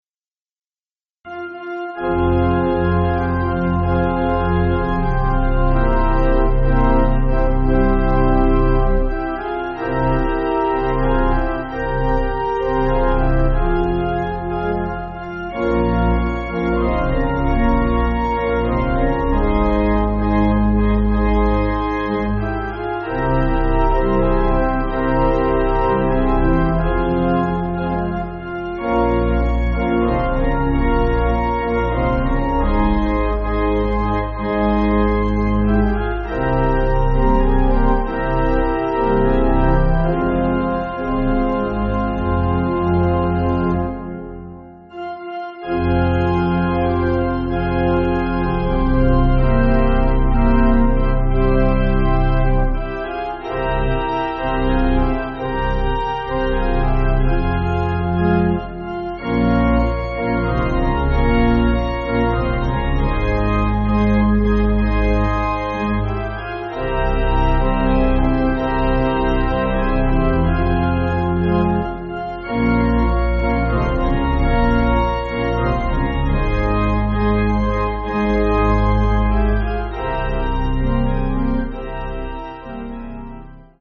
Organ
(CM)   5/Fm